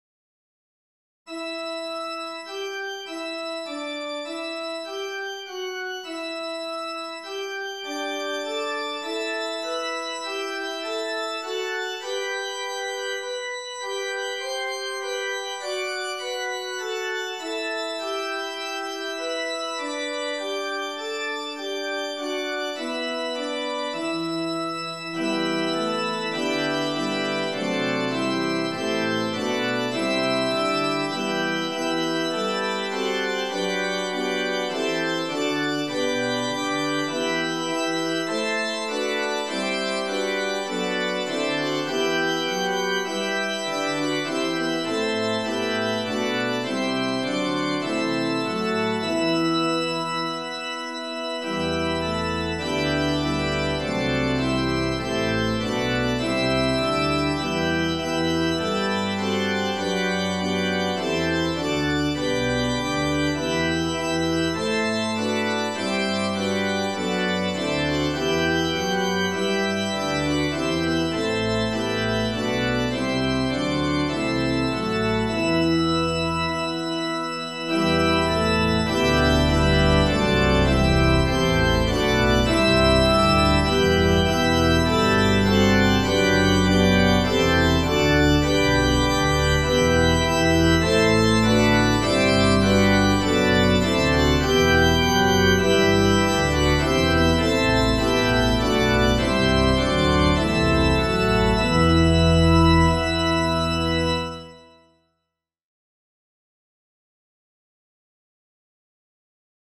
Toleranční kostel, Velká Lhota (dříve také Hrubá Lhota)
« Nakonec jsme si zazpívali starou předreformační píseň